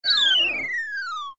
audio: Converted sound effects
ENC_Lose_shrinking.ogg